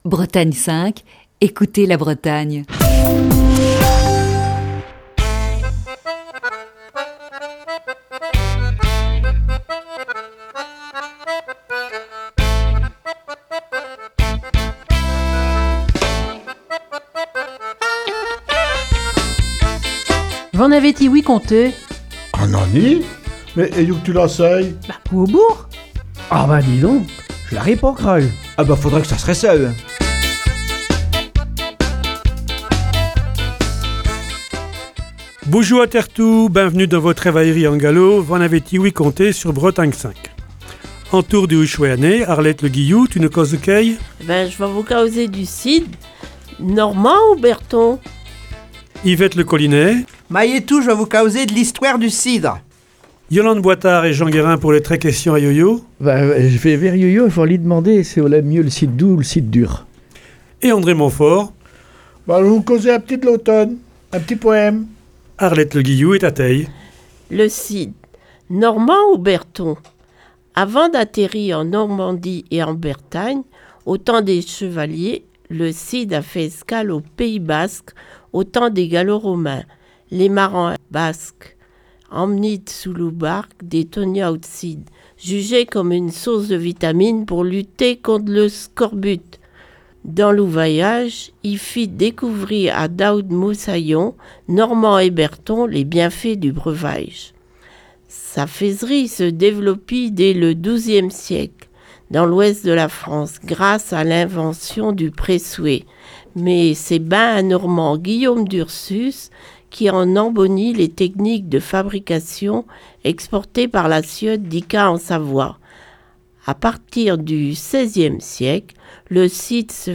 Bretagne 5 vit à l'heure du déconfinement avec encore cette impossibilité de recevoir des invités en studio.